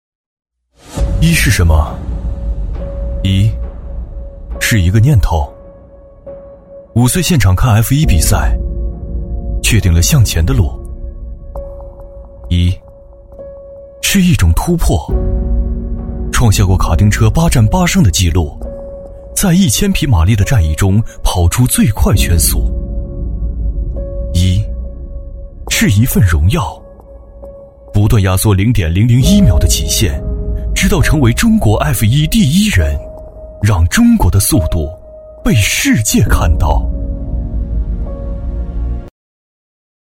男C16-TVC广告 - 安慕希
男C16-年轻质感 沉稳大气
男C16-TVC广告 - 安慕希.mp3